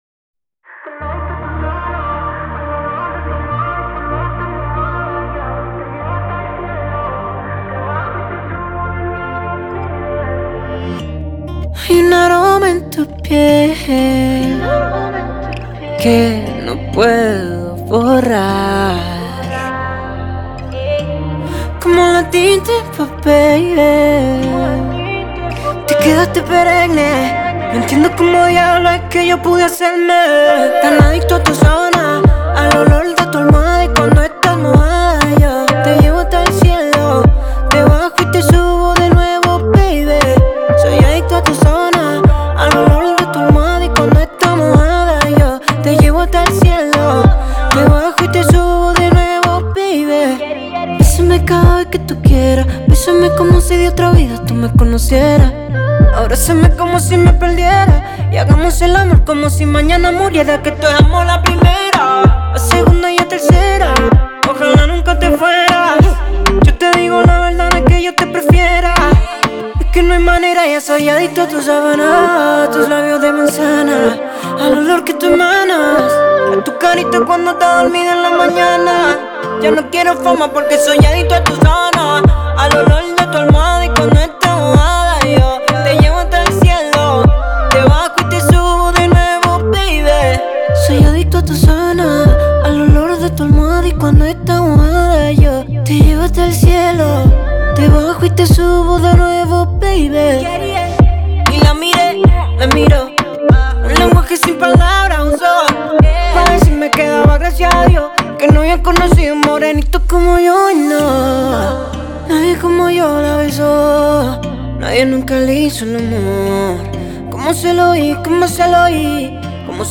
это романтическая песня в жанре латинской поп-музыки